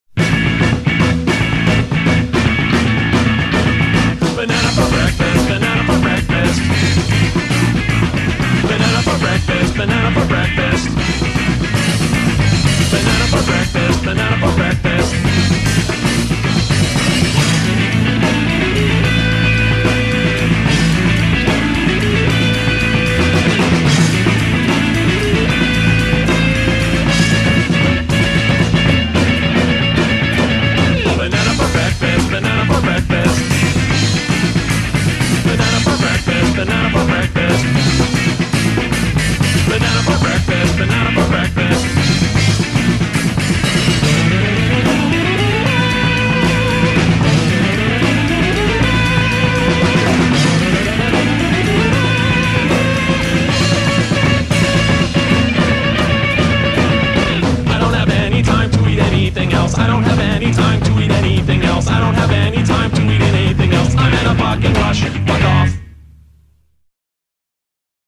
Recorded on: Tascam 424mkIII